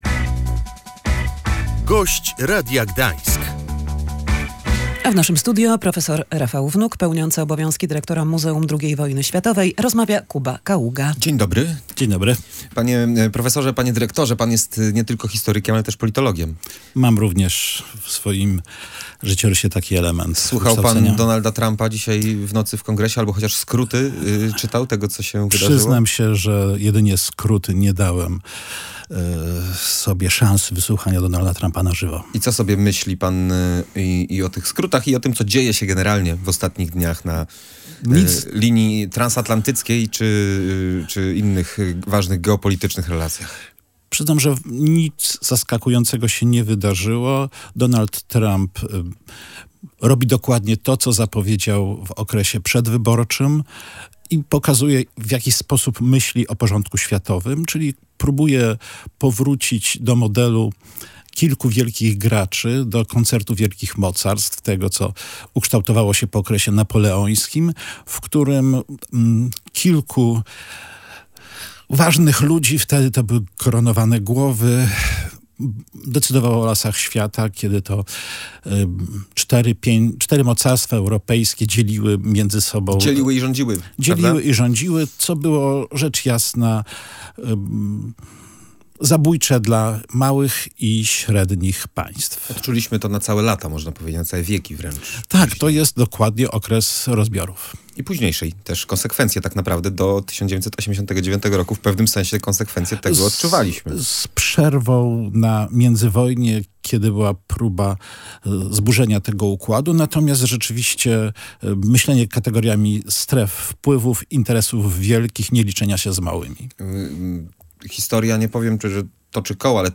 Prezydent Ukrainy nie ma wyjścia, musi usiąść do negocjacji ze Stanami Zjednoczonymi - mówił w Radiu Gdańsk historyk i politolog